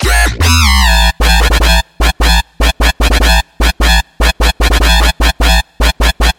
安利风格的Dubstep Drop
标签： 150 bpm Dubstep Loops Bass Wobble Loops 1.08 MB wav Key : Unknown
声道立体声